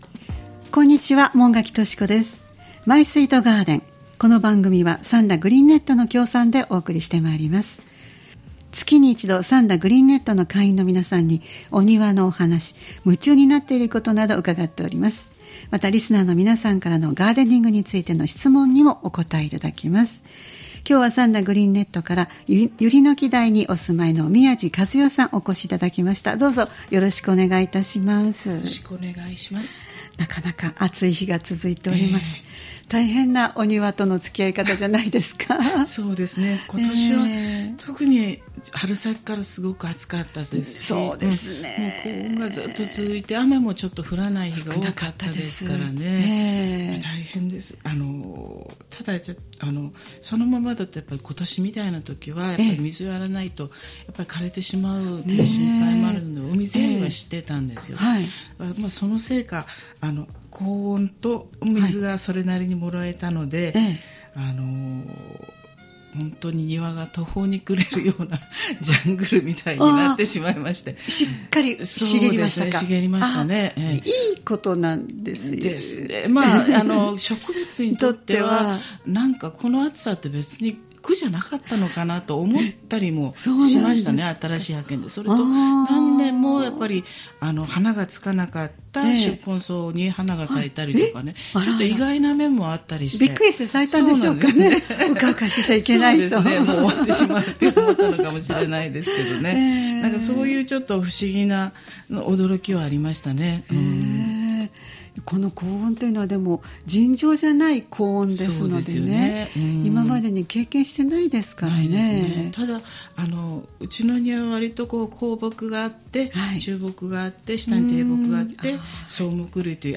毎月第1火曜日は兵庫県三田市、神戸市北区、西宮市北部でオープンガーデンを開催されている三田グリーンネットの会員の方をスタジオにお迎えしてお庭の様子をお聞きする「マイスイートガーデン」をポッドキャスト配信しています（再生ボタン▶を押すと番組が始まります）